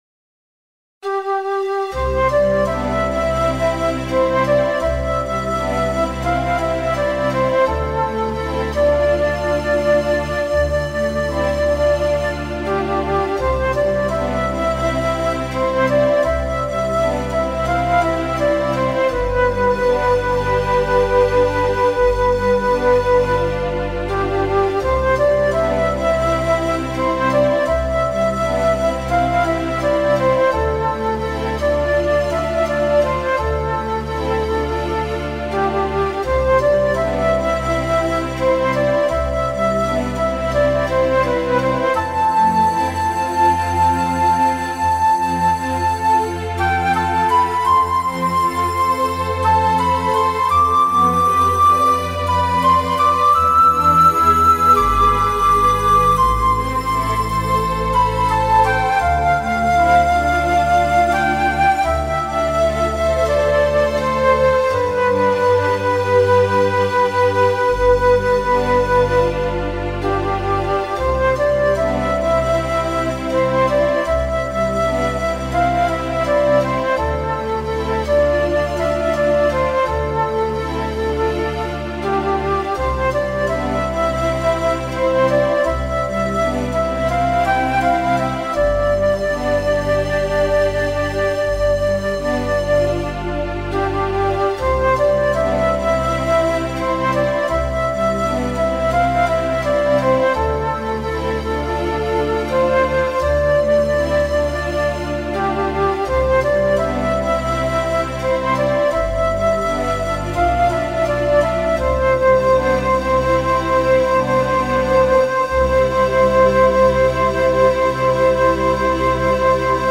クラシックロング穏やか